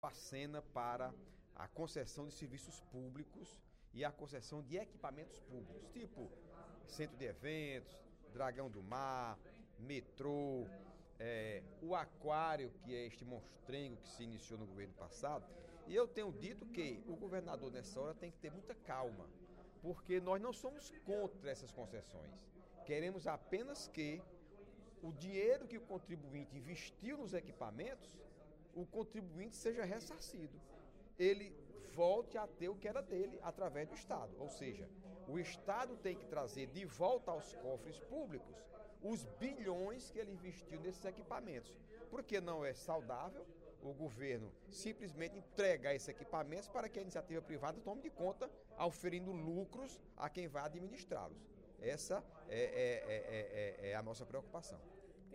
O deputado Heitor Férrer (PDT) avaliou, durante o primeiro expediente da sessão plenáriadesta sexta-feira (19/06), a possibilidade de equipamentos públicos serem transferidos para a iniciativa privada, como tem sido sugerido o governador Camilo Santana.